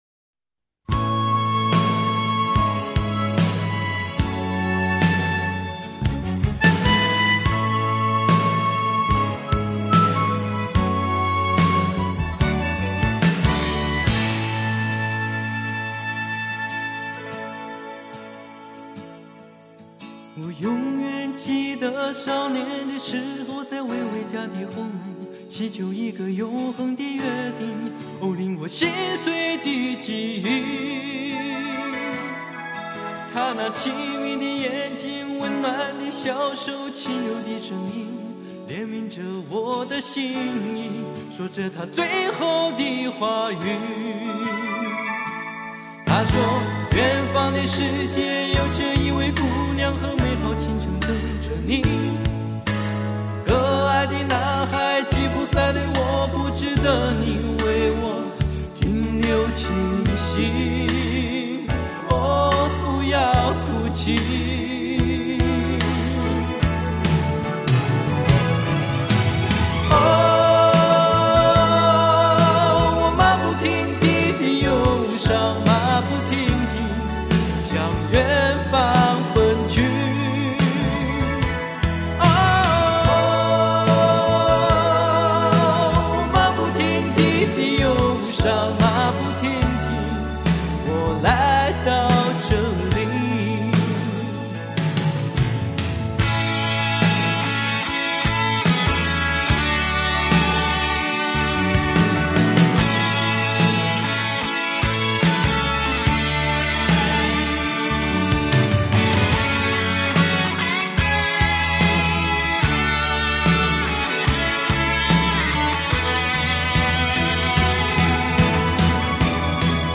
弦音录音室